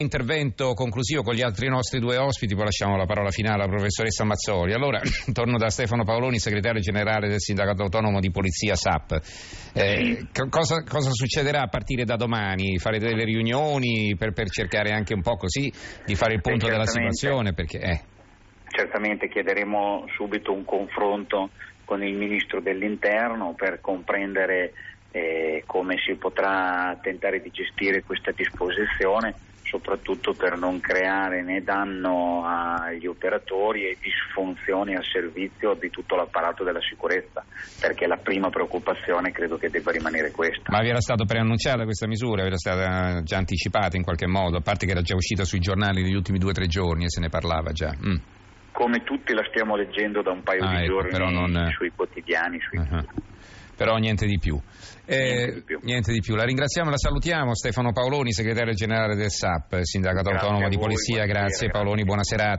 è intervenuto ai microfoni RAI di Radio1, nel corso dell’approfondimento della trasmissione Tra poco in Edicola.